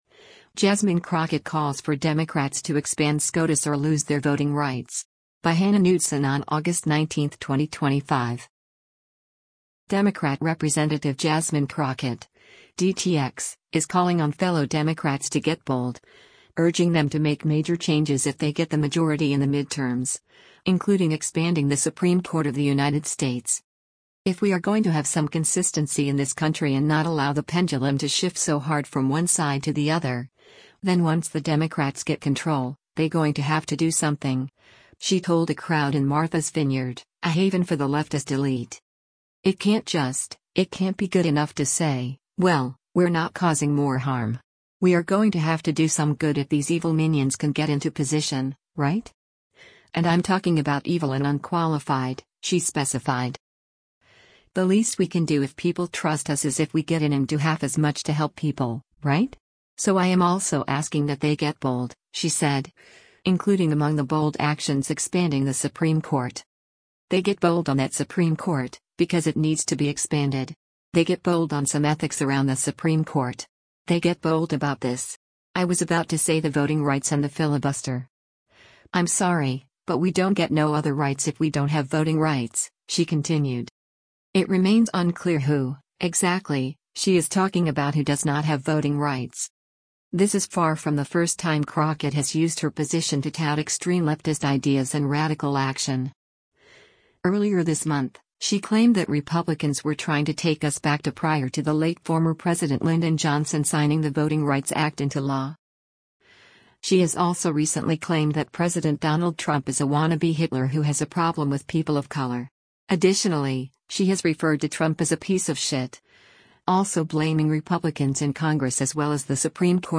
“If we are going to have some consistency in this country and not allow the pendulum to shift so hard from one side to the other, then once the Democrats get control, they going to have to do something,” she told a crowd in Martha’s Vineyard — a haven for the leftist elite.